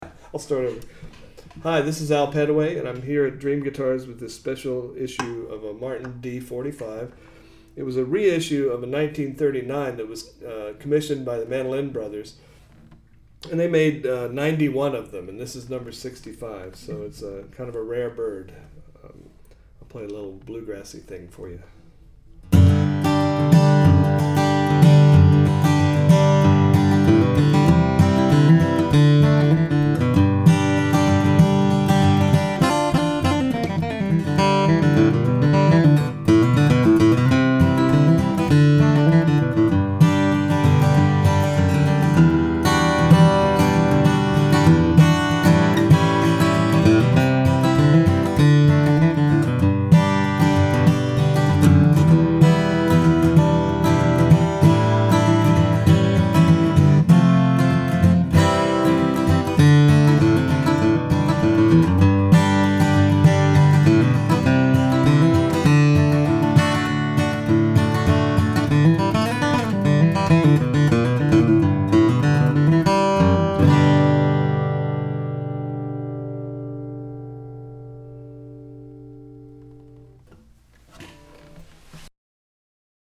1990 Martin D-45, 1 of 5! Custom Mandolin Bros 1939 Reissue Brazilian/Sitka - Dream Guitars
Mandolin Bros. was the first to ask Martin to recreate the great sound of the prewar guitars by having them remade with scalloped braces, T-bar, V neck and all the wonderful 45 Style Appointments.